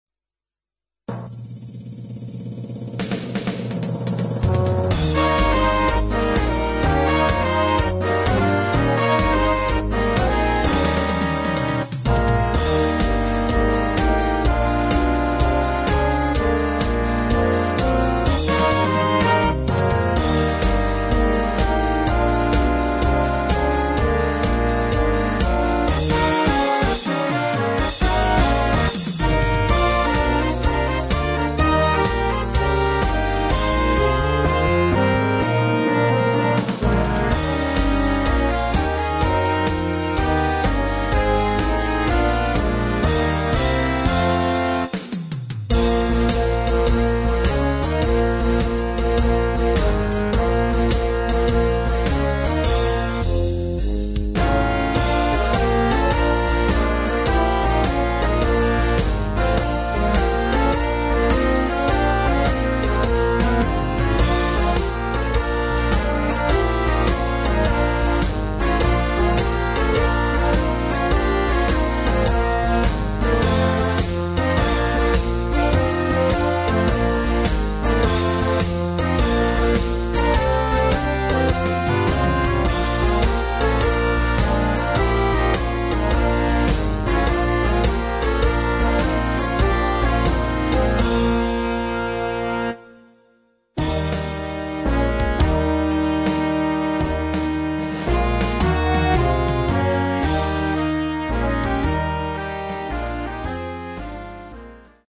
für Jugendblasorchester
2:50 Minuten Besetzung: Blasorchester PDF